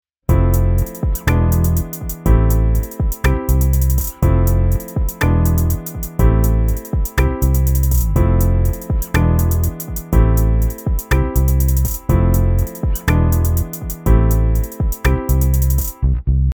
現在のキーはCmajになっていますが、ピアノで入力したコードのキーとなる「Aマイナー」に変更します。
▶Session Playerのベースを追加した状態
このように設定したキーやコード進行に沿って、自然な演奏内容が生成されます。
Logic-11-Bass0.mp3